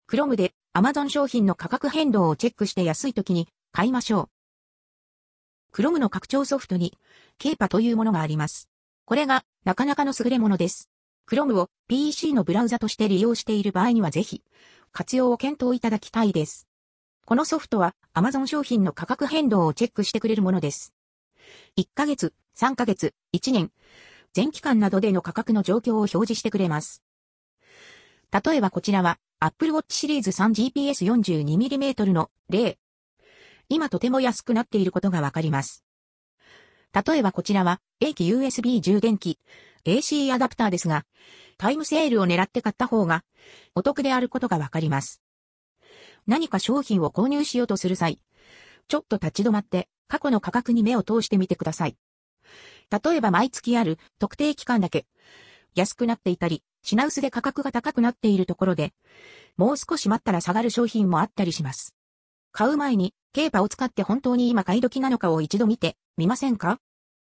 amazon_polly_254.mp3